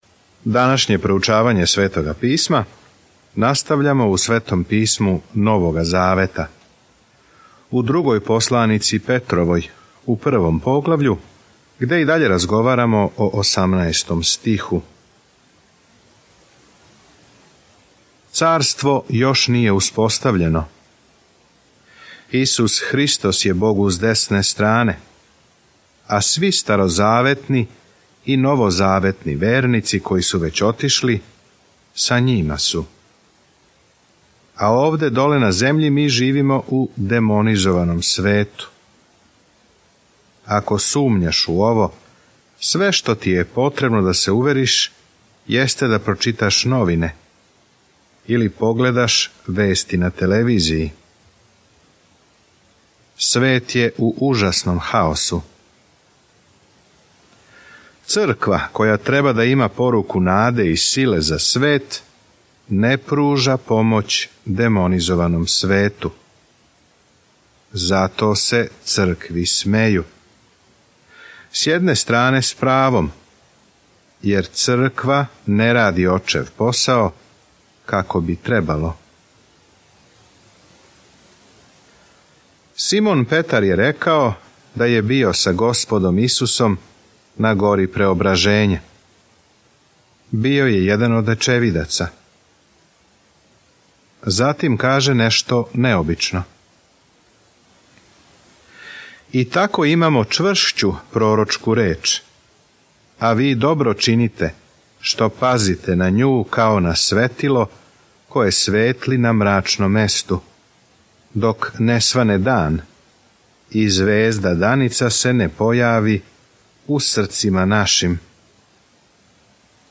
Петрову док слушате аудио студију и читате одабране стихове из Божје речи.